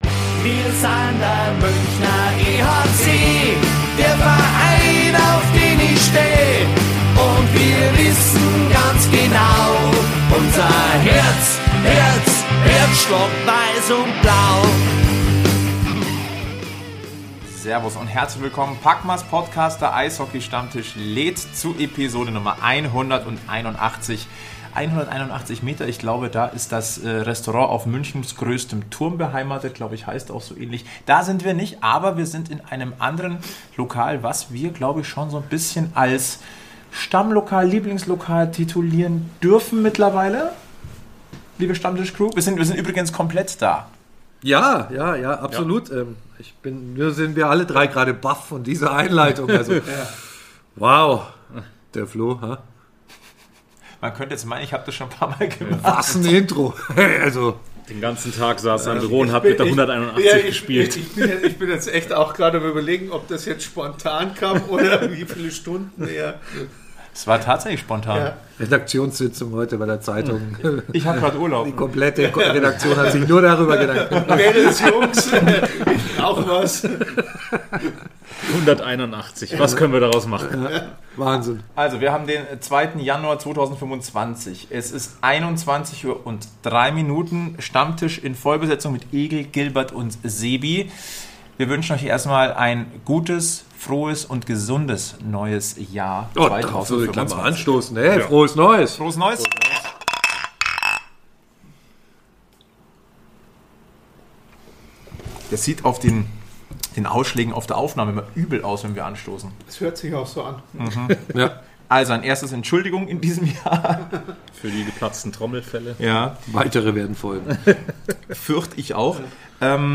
Wir sind es und haben uns tatsächlich in voller Besetzung zusammengefunden – im Lokal "Land in Sonne 1" am Münchner Westpark, das am 07.02.2025 zum Schauplatz von etwas Historischem im Münchner Eishockey-Kosmos wird, bei dem ihr dabei sein könnt.